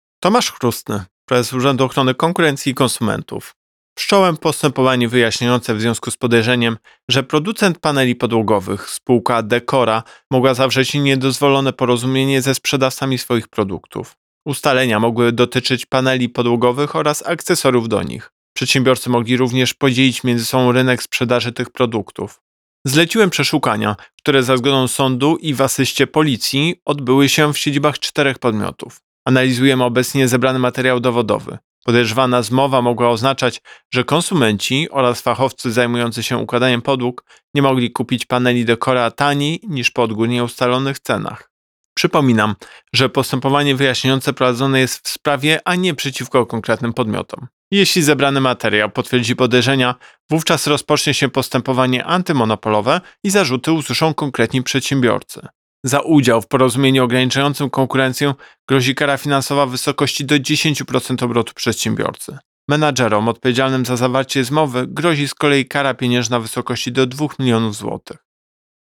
Wypowiedź Prezesa UOKiK Tomasza Chróstnego Przeszukania odbyły się w siedzibach spółek: Decora, Decora Trade, Sklepy Komfort oraz Bel – Pol.